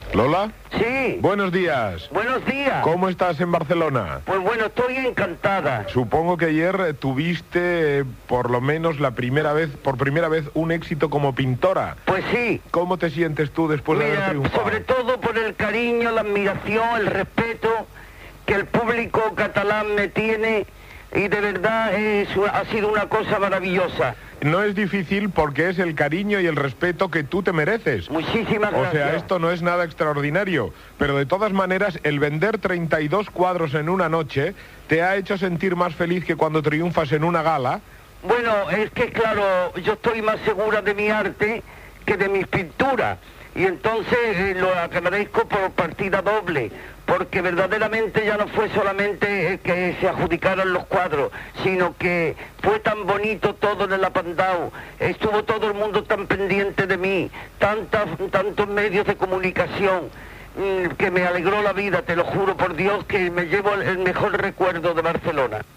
Entrevista a la cantant Lola Flores que havia inaugurat una exposició de pintures